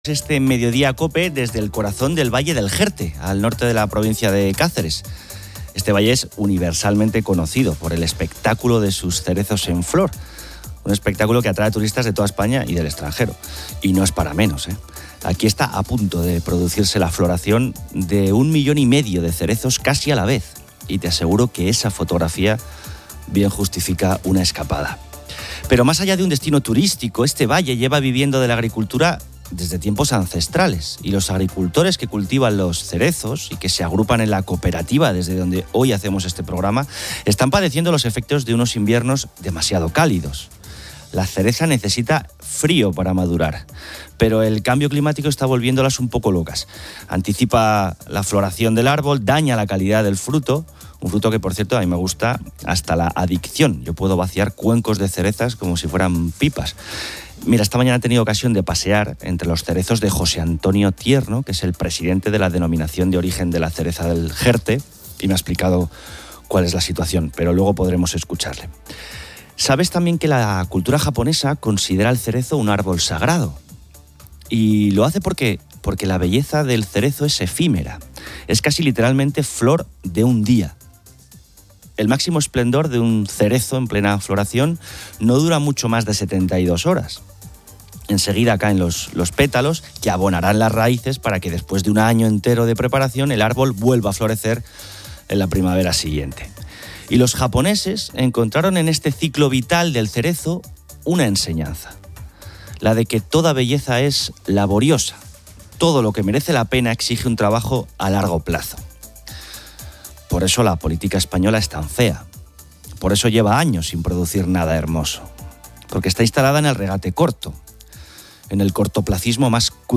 Este mediodía acope desde el corazón del Valle del Jerte, al norte de la provincia de Cáceres. Este valle es universalmente conocido por el espectáculo de sus cerezos en flor.